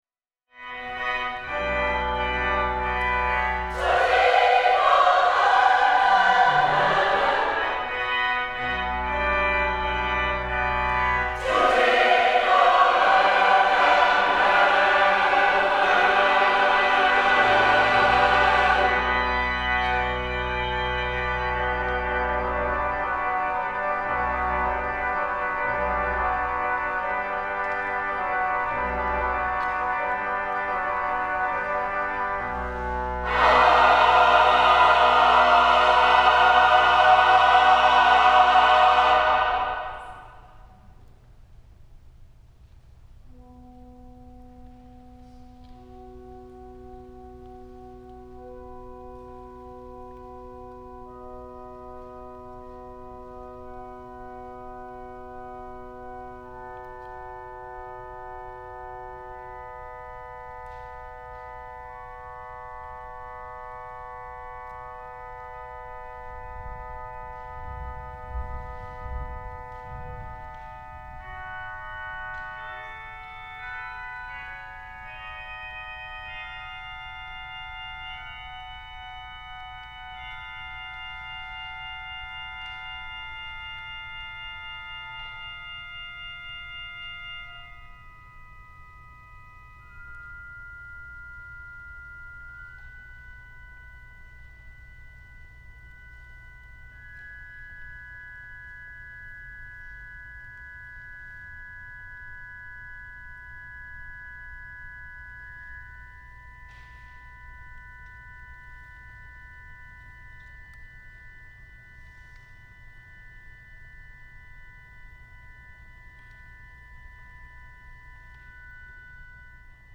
chorus (SATB) & organ